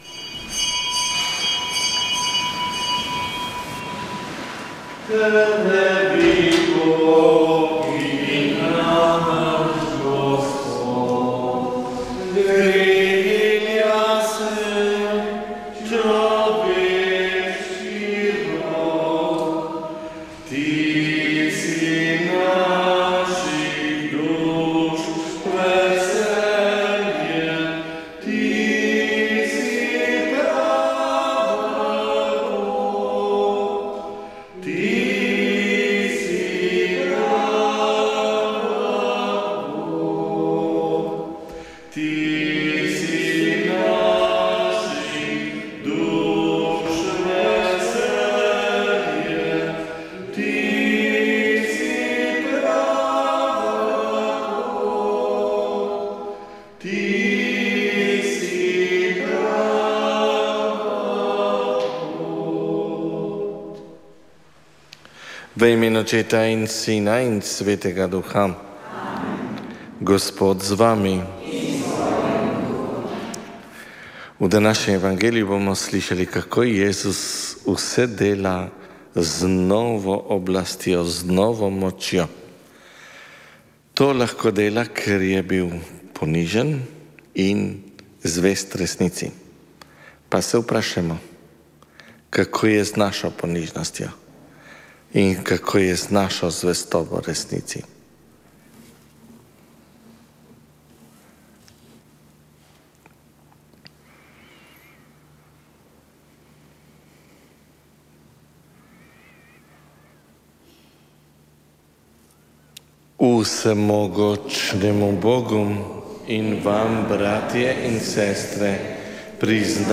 Sveta maša
Sv. maša s podružnične cerkve svete Trojice na Vrhniki
Prenos sv. maše s podružnične cerkve svete Trojice na Vrhniki.